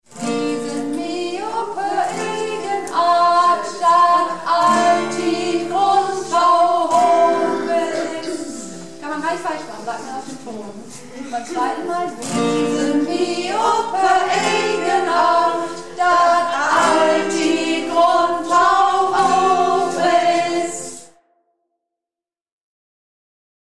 Sopran-Teil